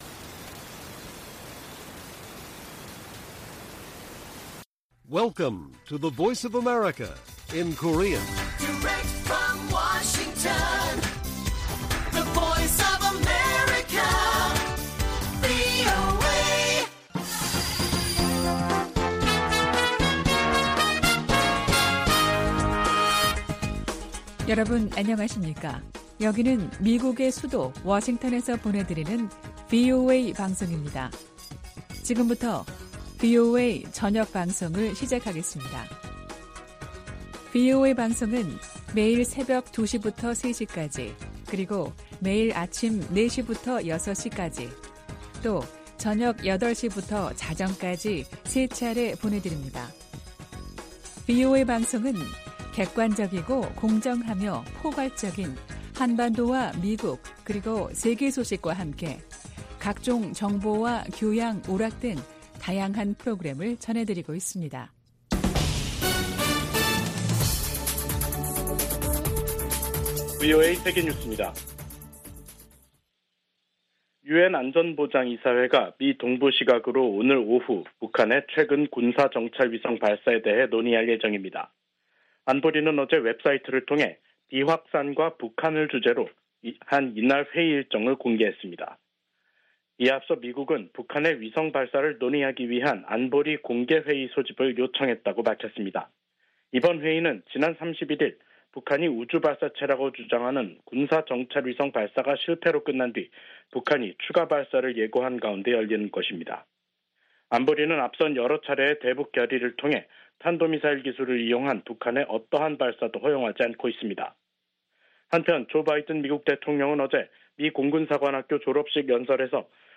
VOA 한국어 간판 뉴스 프로그램 '뉴스 투데이', 2023년 6월 2일 1부 방송입니다. 유엔 안보리가 미국의 요청으로 북한의 위성 발사에 대한 대응 방안을 논의하는 공개 회의를 개최합니다. 미국과 한국 정부가 북한 해킹 조직 '김수키'의 위험성을 알리는 합동주의보를 발표했습니다.